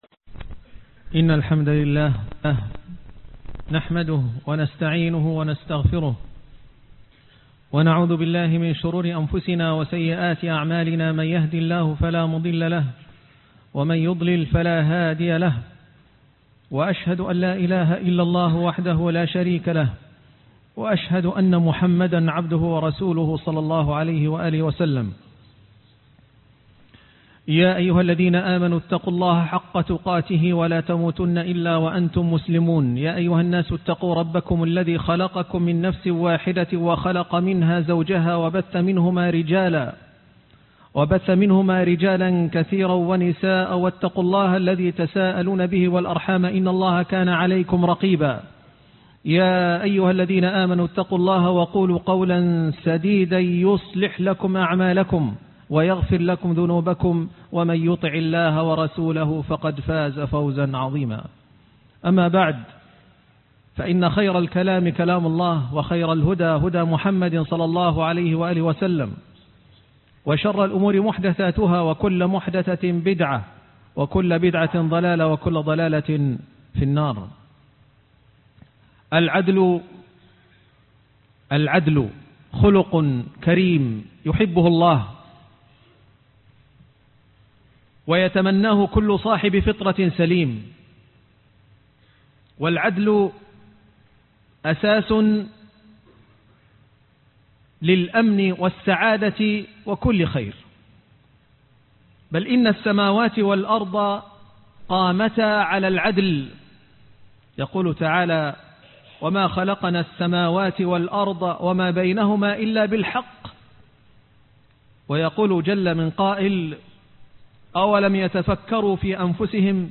محمد رسول الله (روائع الأخلاق) العدل - خطبة الجمعة